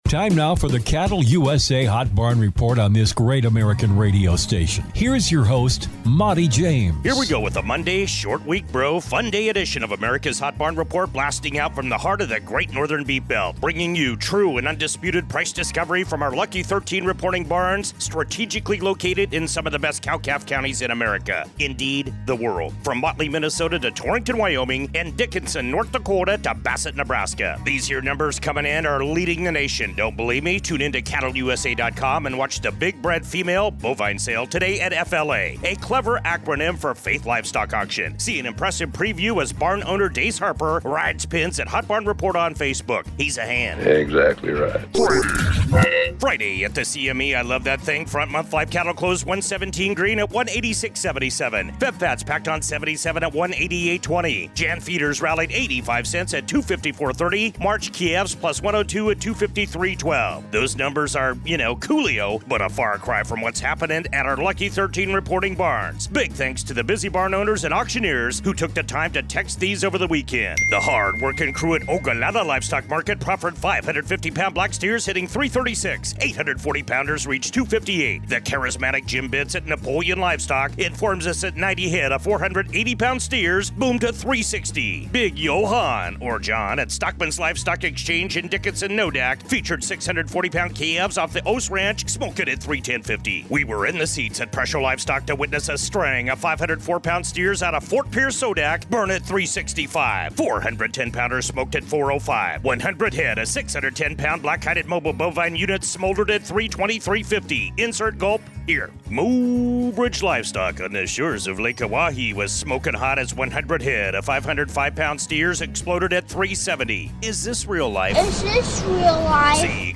The Hot Barn Report features interviews with industry leaders, market analysts, producers and ranchers and features True Price Discovery from salebarns in Great Northern Beef Belt and across the nation.